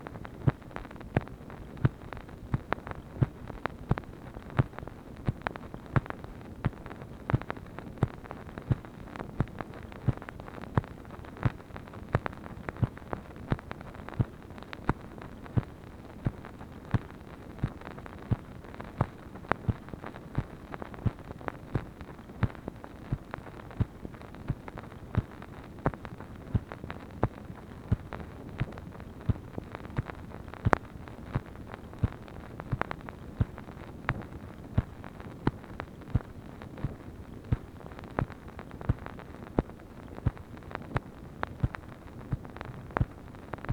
MACHINE NOISE, February 8, 1964
Secret White House Tapes